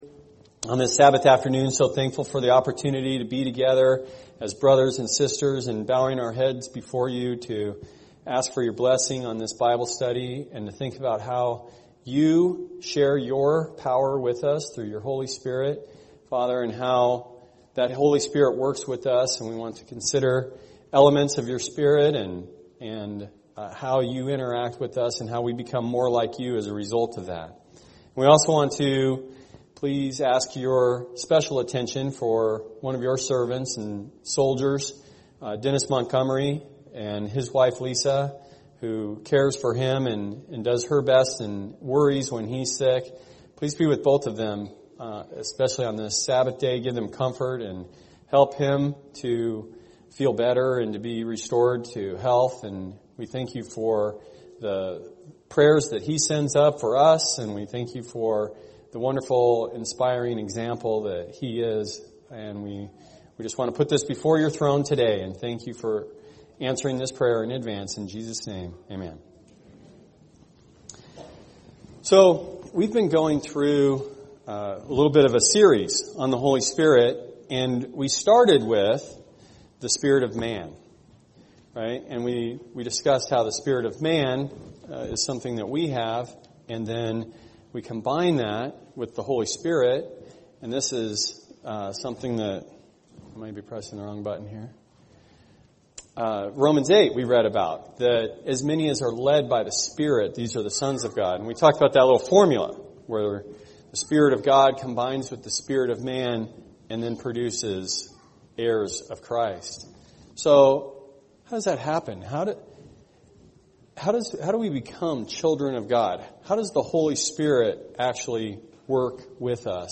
We will discuss how the Holy Spirit leads us and gives us the guidance, direction, protection, and correction we need to fulfill the vision and mission of the Church. A Bible study.